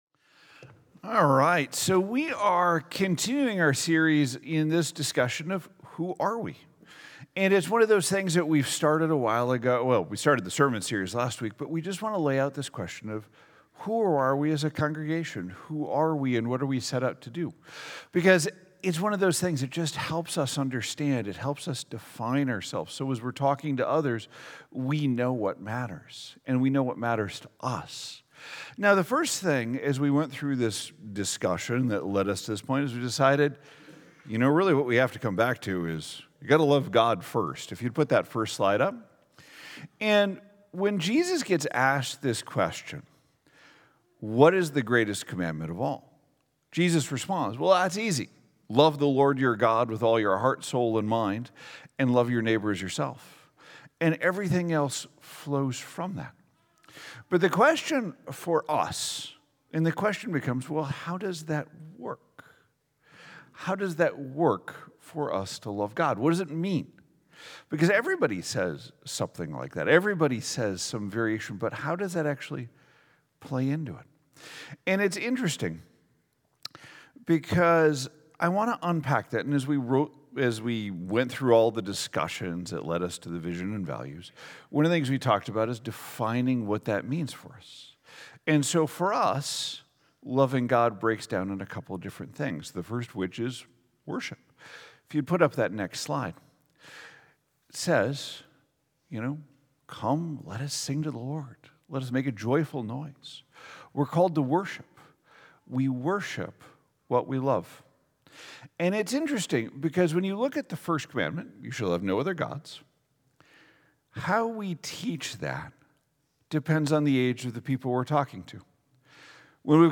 2025 Who Are We God Love Prayer Sunday Morning Sermon Series